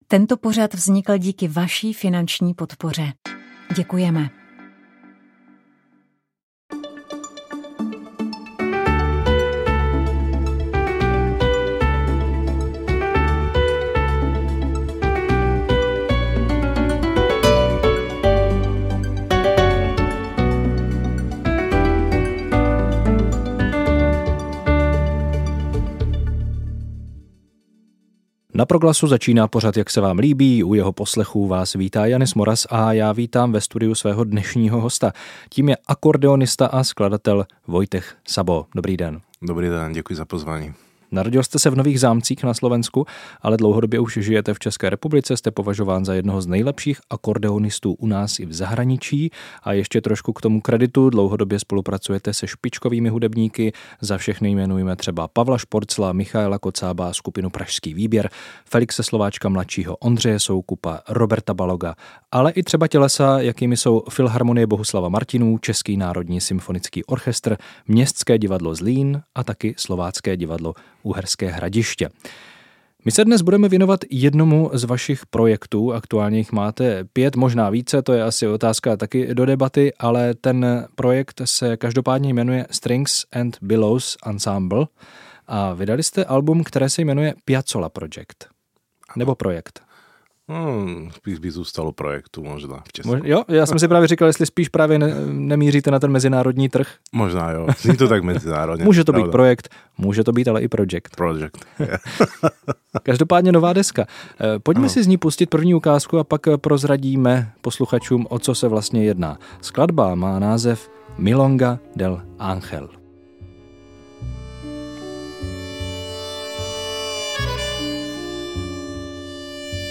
Rozhovor s finským harmonikovým kvartetem